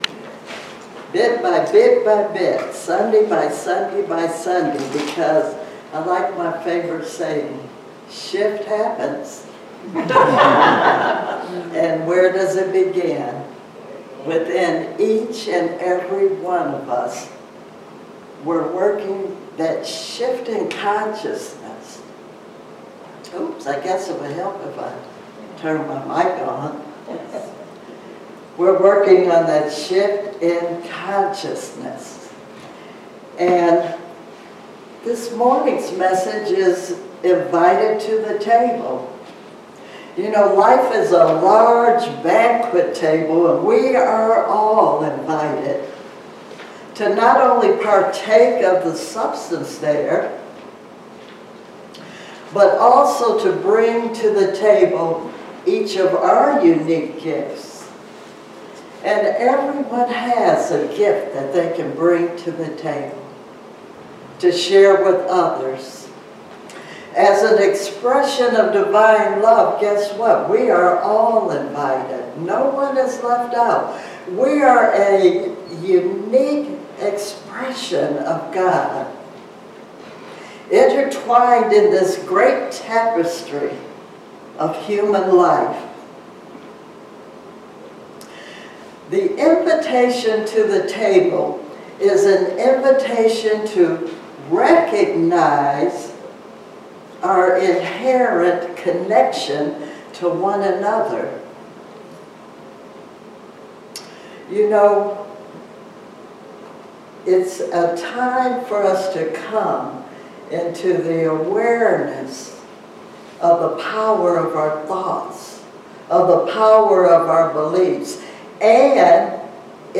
Spiritual Leader Series: Sermons 2024 Date